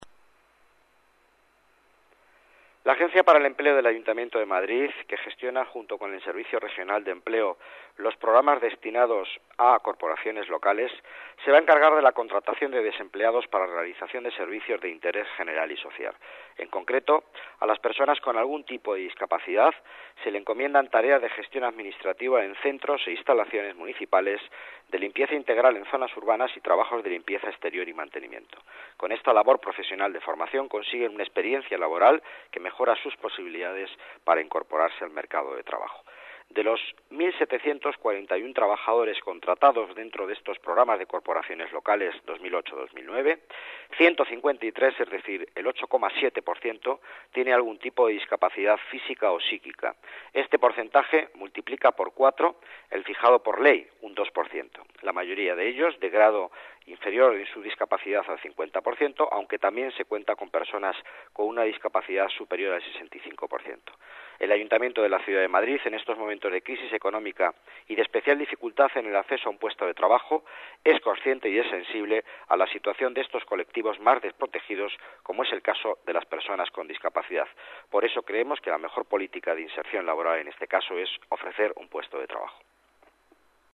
Nueva ventana:Declaraciones del delegado de Economía, Miguel Ángel Villanueva